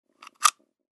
Пулю поместили в магазин для патронов